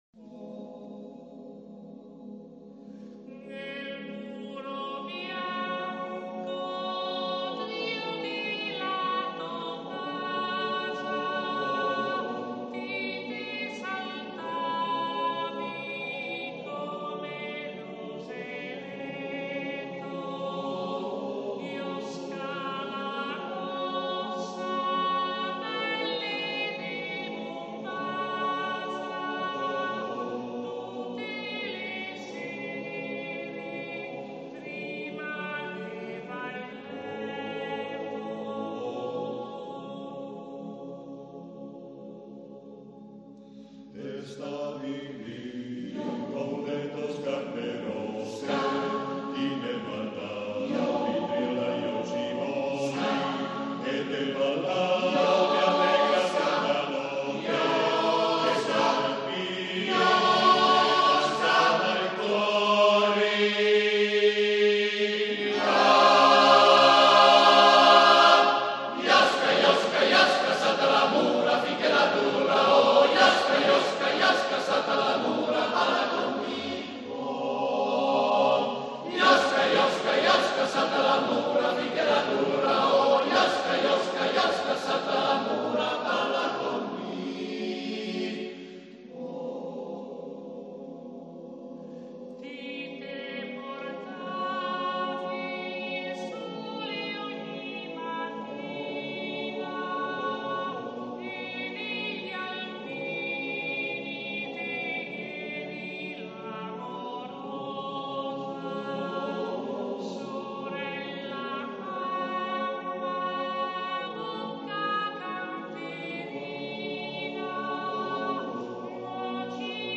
Canto degli alpini veneti